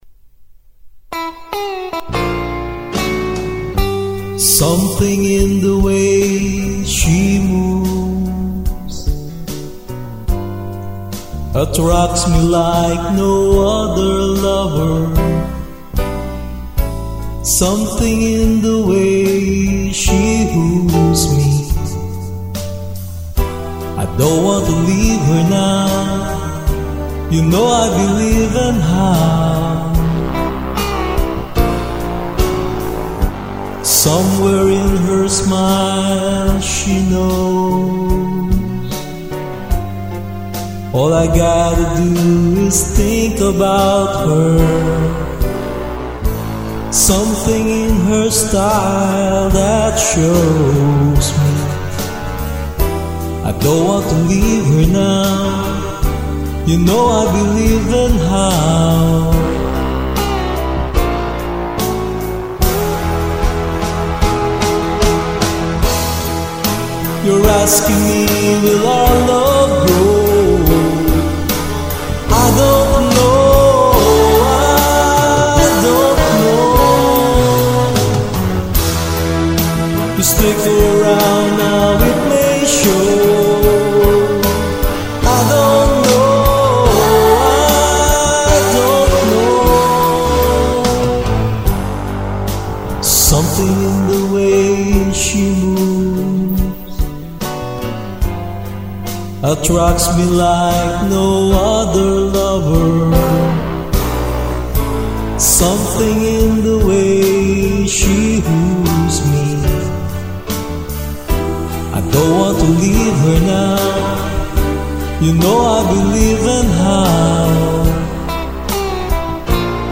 1969 Genre: Rock